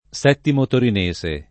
settimo [S$ttimo] agg. — anche top.: Settimo Torinese [